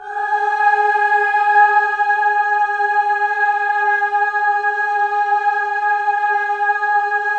VOWEL MV13-R.wav